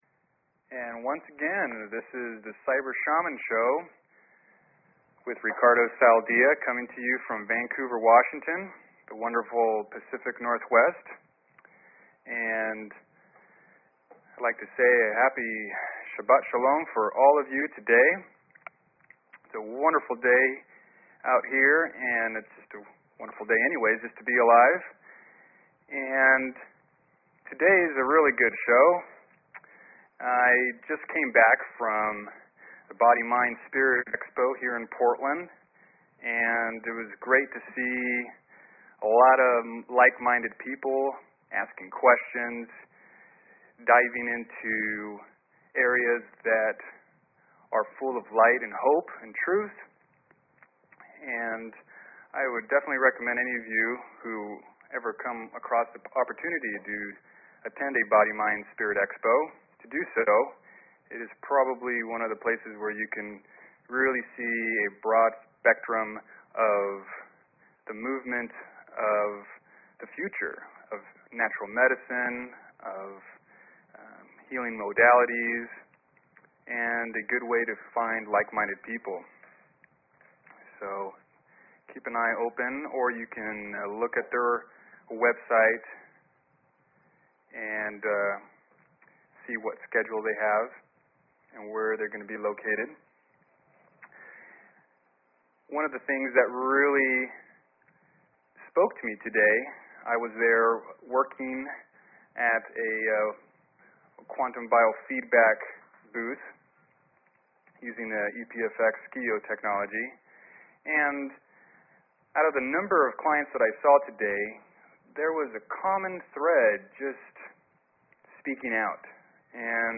Talk Show Episode, Audio Podcast, Cyber_Shaman and Courtesy of BBS Radio on , show guests , about , categorized as